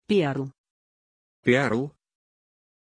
Pronunciation of Pearl
pronunciation-pearl-ru.mp3